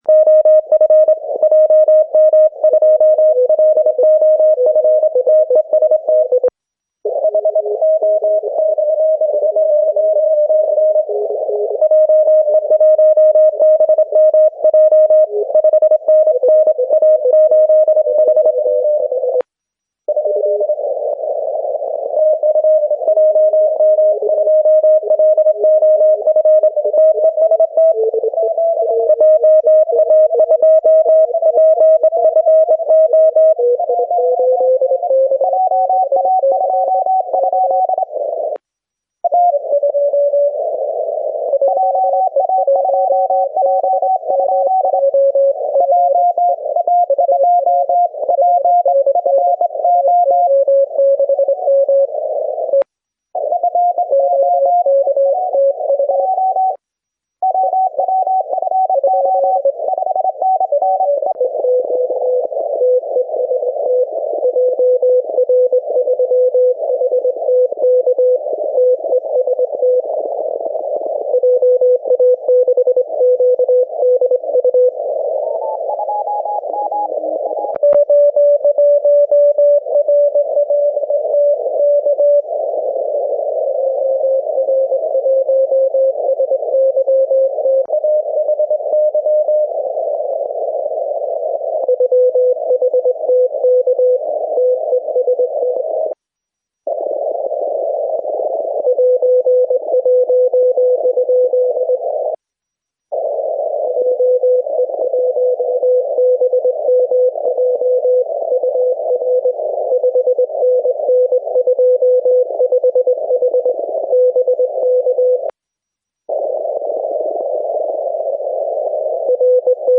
One of the recordings of QSOs on 7 MHz CW at All JA Contest 2014, Apr. 26, around 2340 LMT. Two YL stations have been captured.